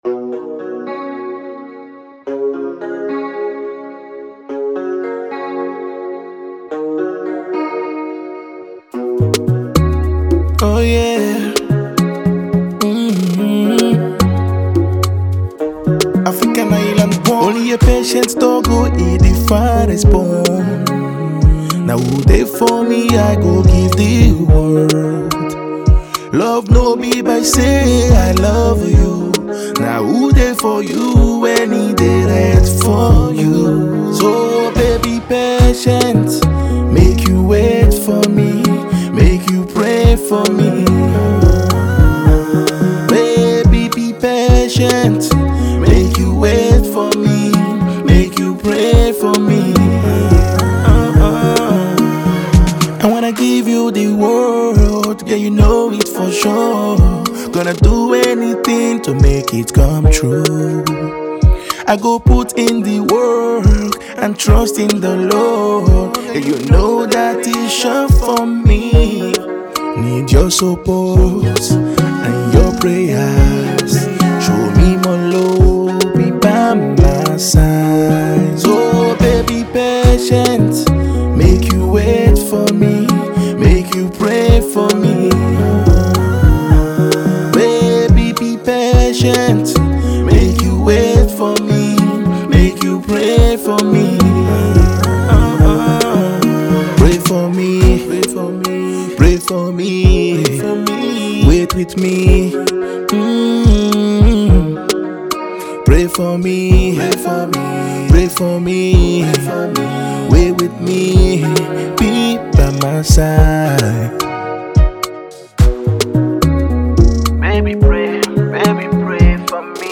sings his heart out in the old traditional way